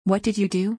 youの脱落 What did you do.